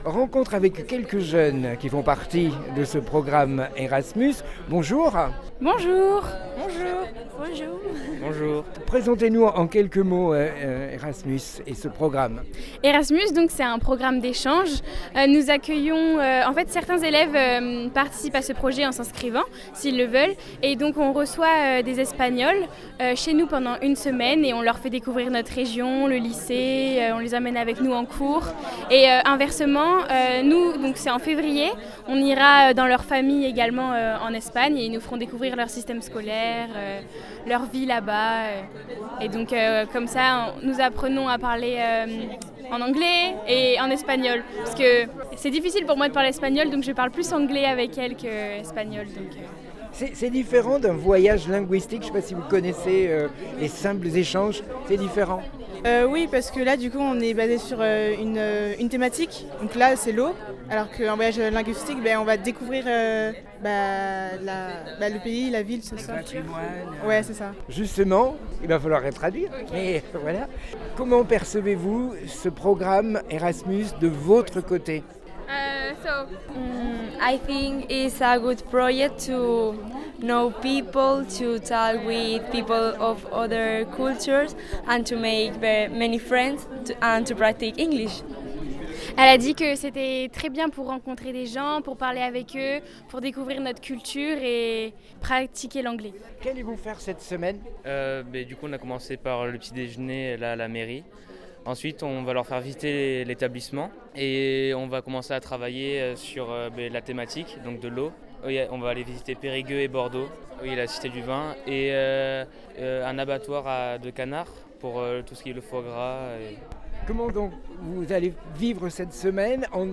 Des jeunes expriment leurs satisfaction dans le cadre du programme ERASMUS